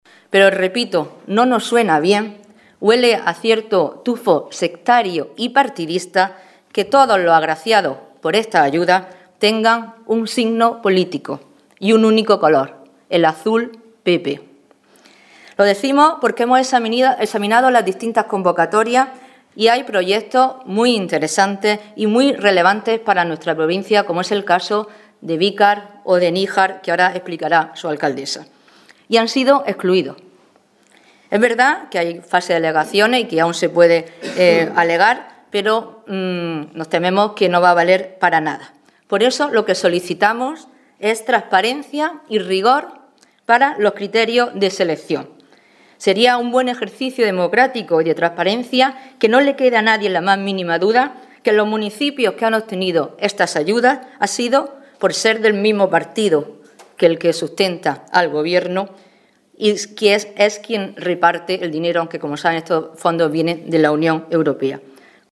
Rueda de prensa del PSOE de Almería sobre los Fondos Edusi